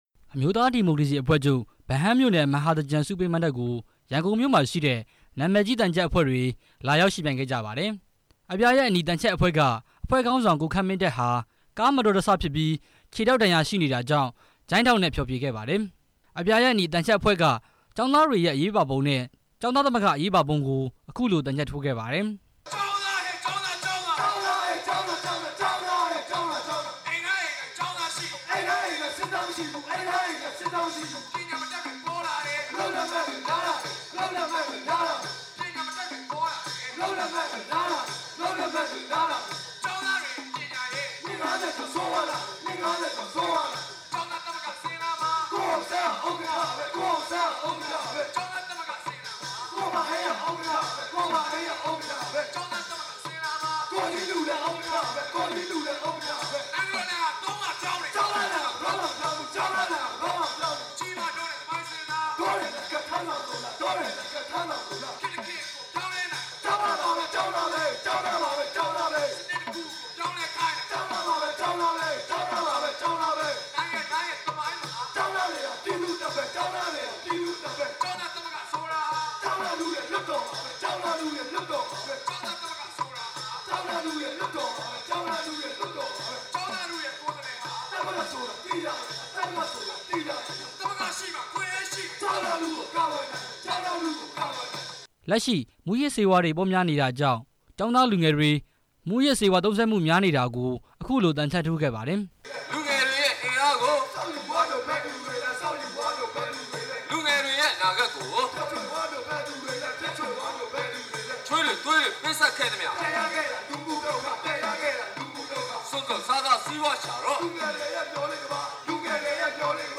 ရန်ကုန်တိုင်းဒေသကြီး ဗဟန်းမြို့နယ် ဗိုလ်စိန်မှန်အားကစားကွင်းမှာ အမျိုးသားဒီမိုကရေစီဖွဲ့ချုပ်က ကျင်းပတဲ့ မဟာသြင်္ကန် ဆုပေးမဏ္ဍပ်မှာ သြင်္ကန်ဒုတိယအကြတ်နေ့ မနေ့ညက အပြာရဲ့အနီ သံချပ်အဖွဲ့လာရောက်ယှဉ်ပြိုင်ခဲ့ပါတယ်။